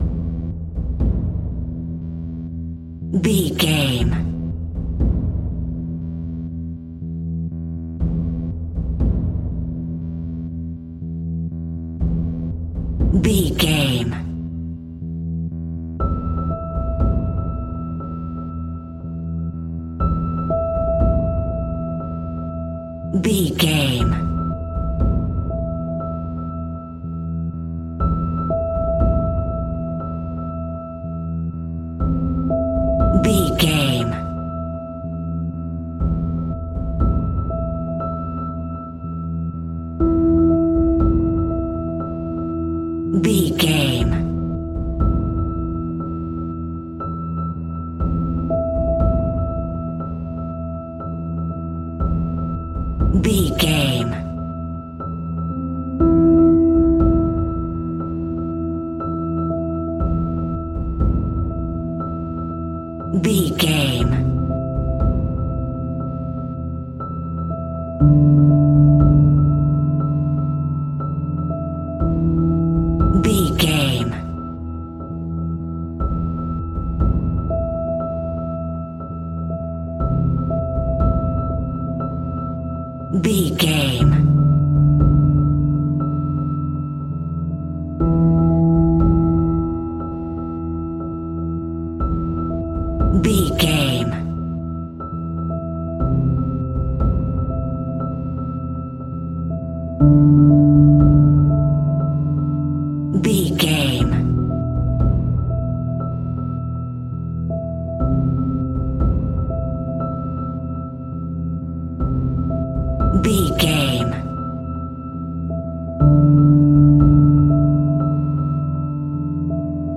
Thriller
Aeolian/Minor
ominous
dark
haunting
eerie
synthesiser
drums
percussion
piano
suspenseful